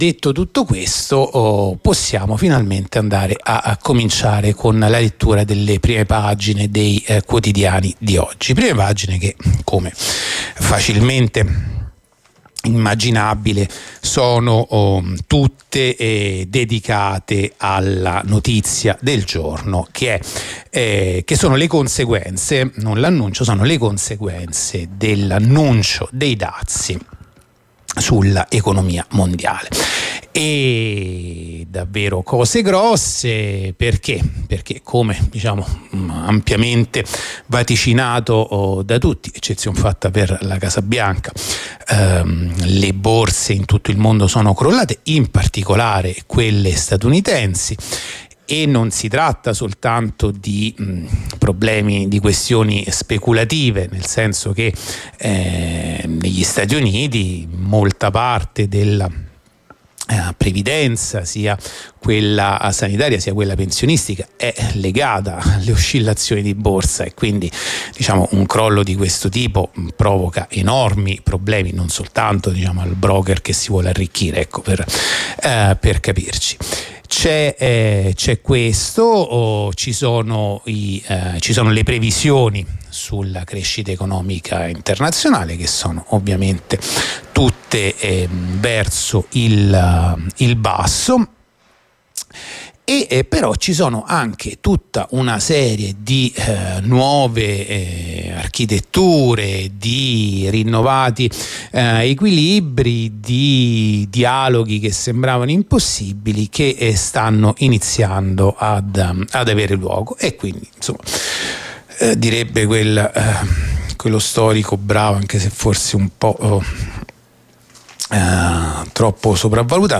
La rassegna stampa di venerdì 4 aprile 2025
La rassegna stampa di radio onda rossa andata in onda venerdì 4 aprile 2025